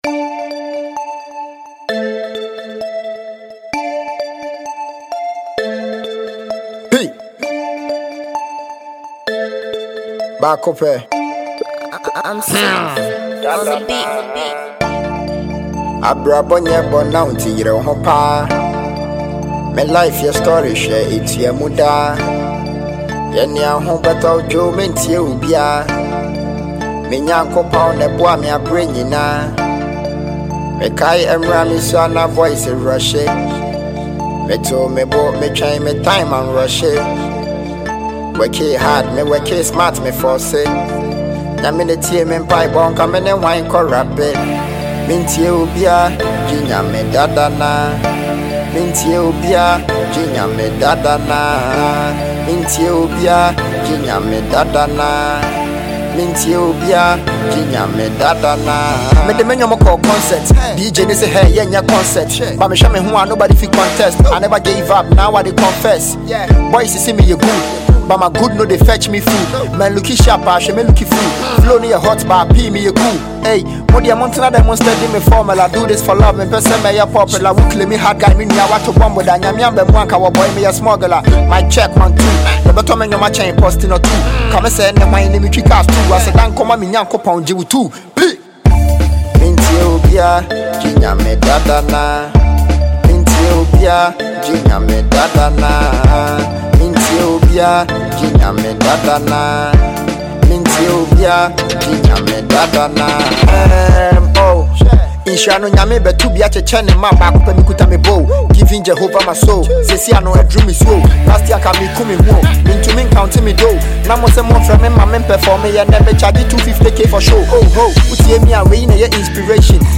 Ghanaian hip hop recording artist